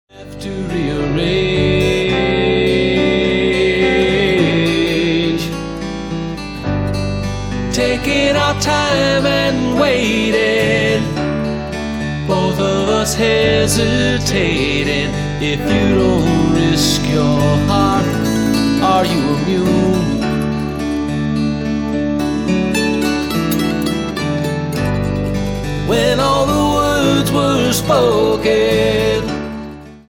12 original Celtic tunes